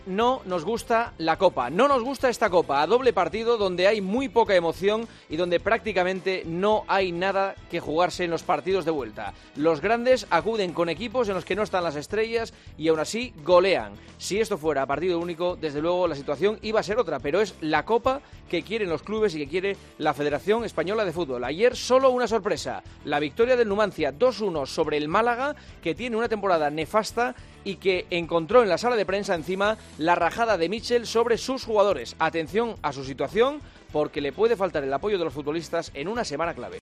Los dieciseisavos de final de la Copa del Rey, en el comentario de Juanma Castaño en 'Herrera en COPE'.